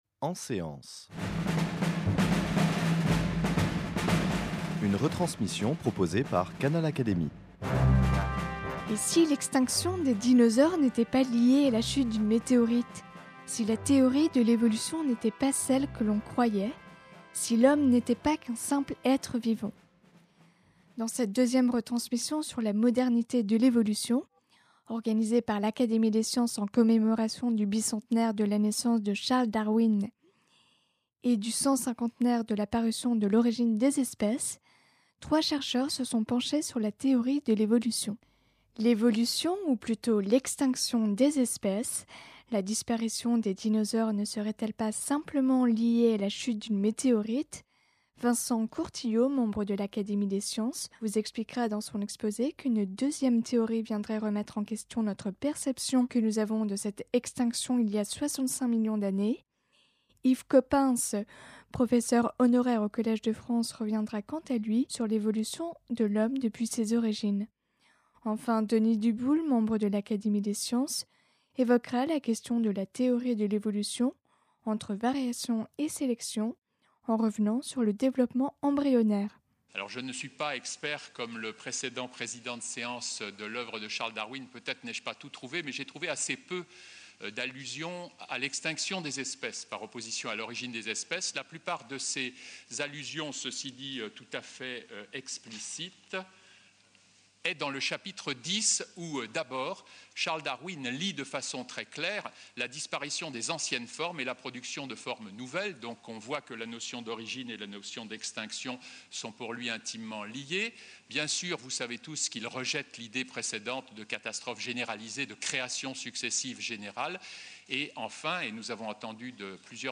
Lors du 200e anniversaire de Charles Darwin et du cent cinquantenaire de la publication de son ouvrage De l’Origine des espèces , l’Académie des sciences a rendu hommage au grand naturaliste anglais, le 30 juin 2009 à l’Institut de France.
Canal Académie présente ce colloque en trois retransmissions.